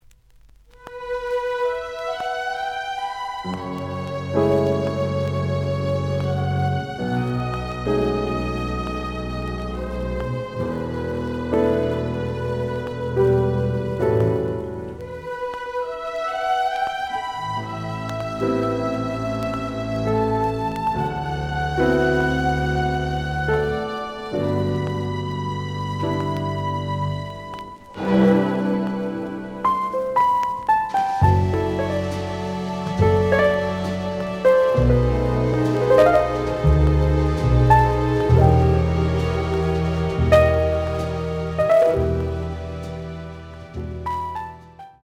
The audio sample is recorded from the actual item.
●Genre: Jazz Funk / Soul Jazz
Slight noise on B side.)